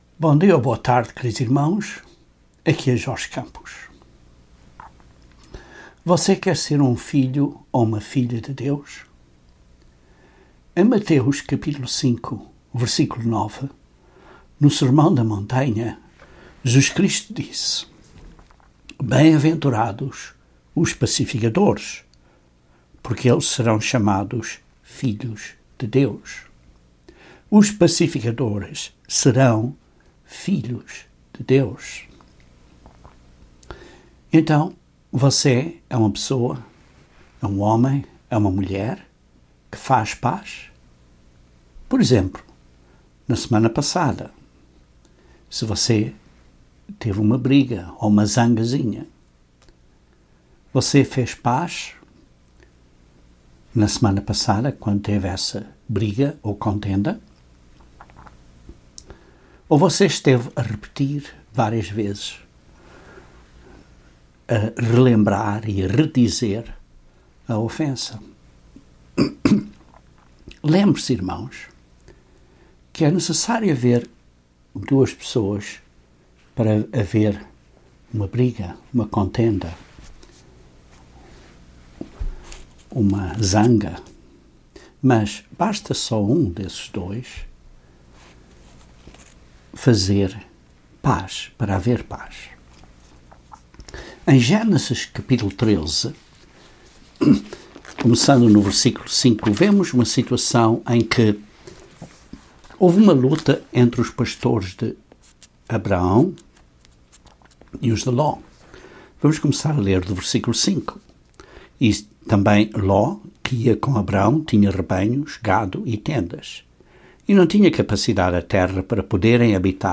Mateus 5:9 diz que os pacificadores serão chamados filhos de Deus. Este sermão descreve como a humildade, a mansidão de sabedoria e fazer da paz um objectivo, são passos críticos em nós nos tornarmos pacificadores.